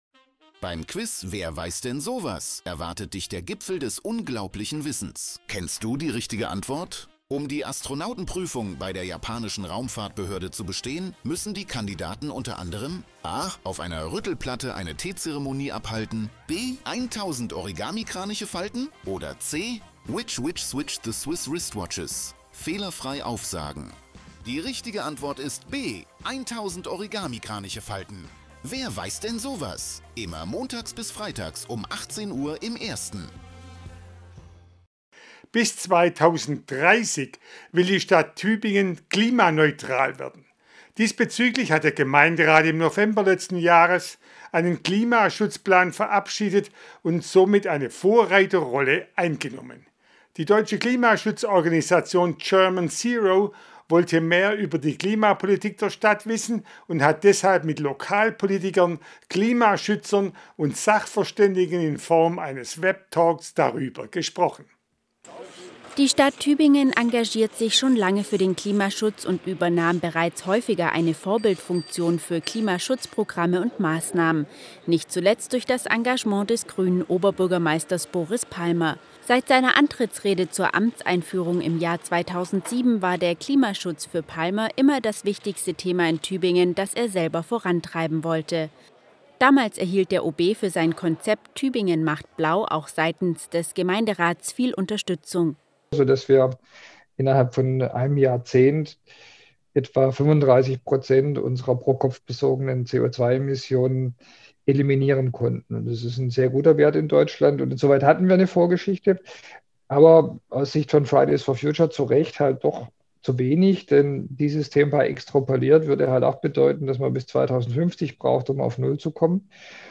Talk mit OB Boris Palmer und anderen